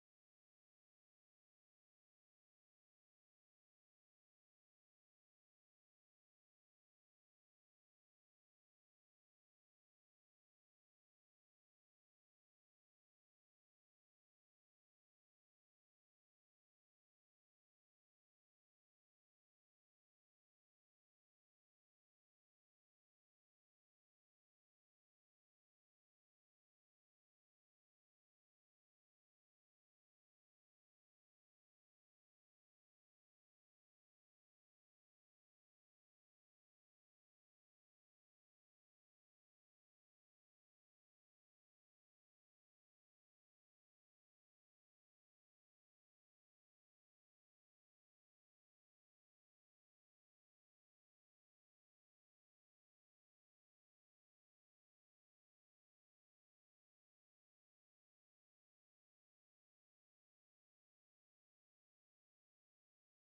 12″ Showcase with Vocals Dubs & Versions.
modern roots steppers
All recorded, mixed & mastered